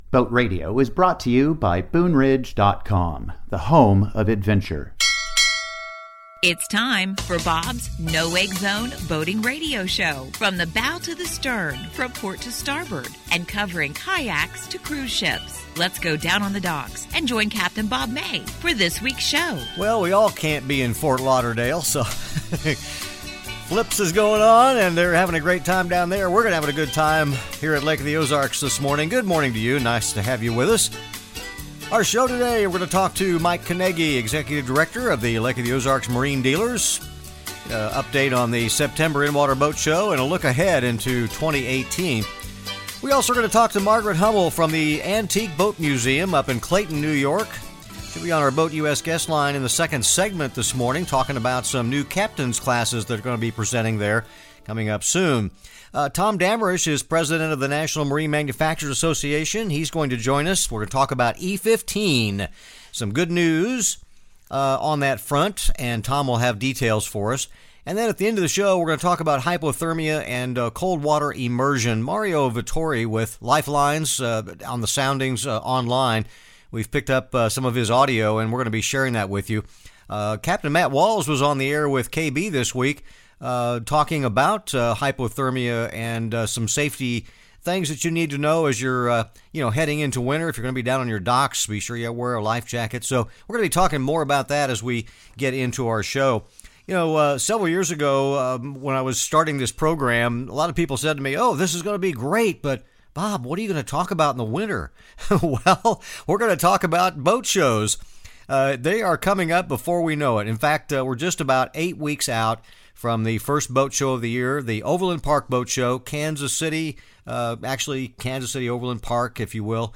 comes to you each week from the Lake of the Ozarks in Missouri. It is a fascinating slice of life and by far the best boating radio show out there.